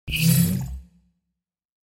دانلود صدای ربات 2 از ساعد نیوز با لینک مستقیم و کیفیت بالا
جلوه های صوتی